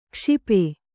♪ kṣipe